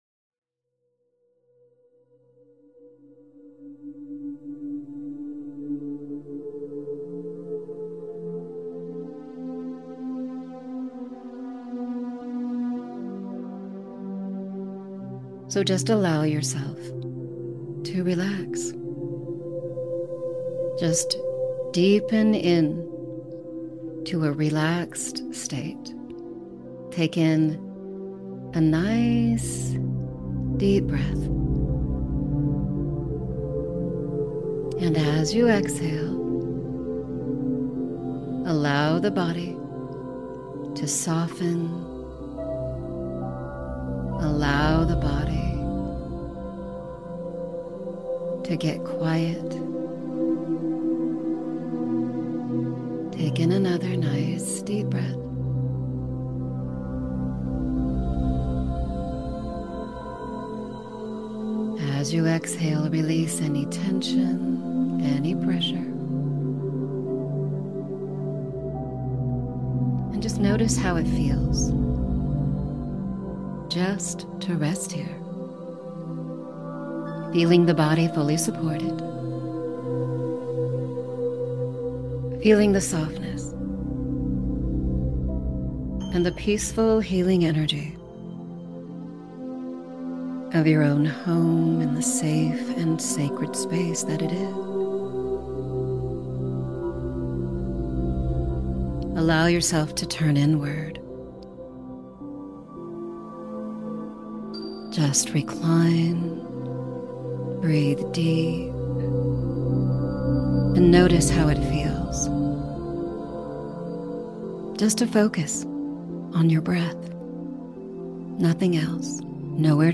Self Hypnosis Session